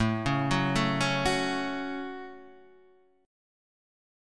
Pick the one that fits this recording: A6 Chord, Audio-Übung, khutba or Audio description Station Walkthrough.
A6 Chord